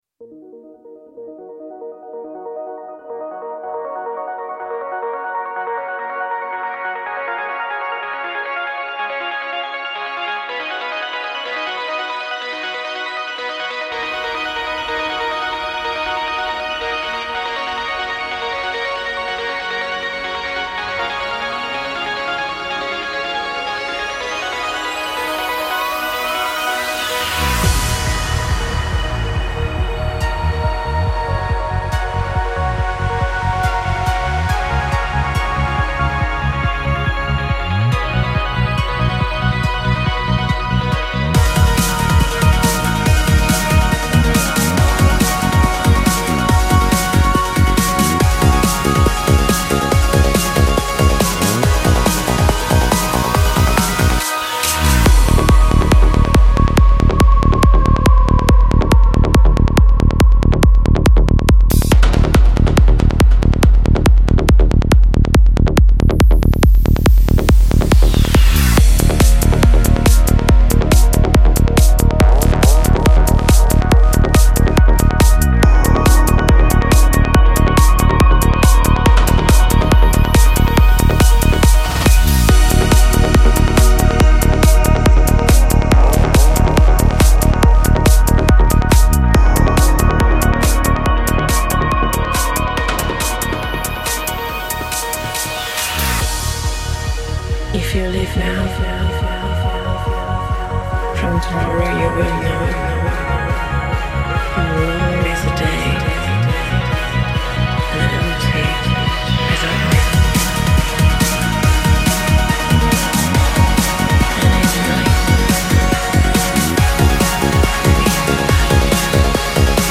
دانلود بیت
ژانر : ملوسایکو
تمپو : 140